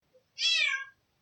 Kucing_Suara.ogg